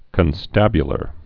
(kən-stăbyə-lər)